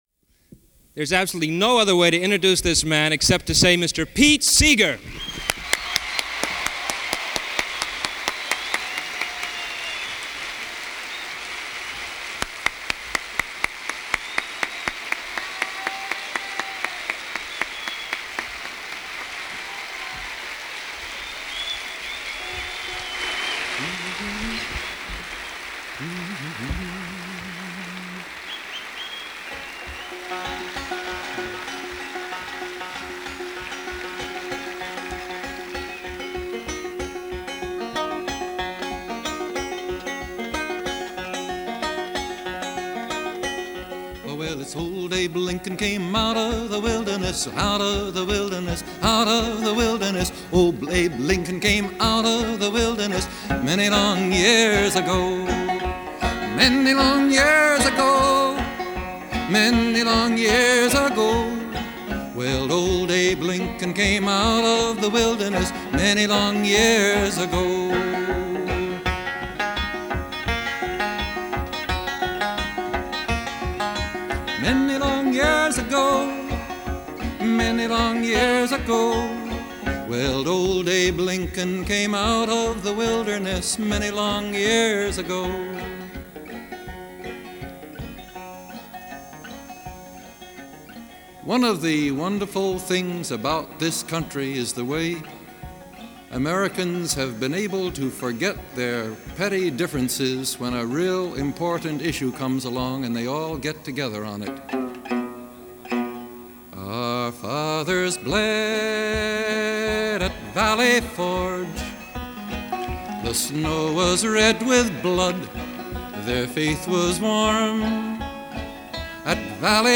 No on Proposition 14 Rally